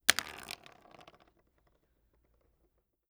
pencil_impact_soft3.wav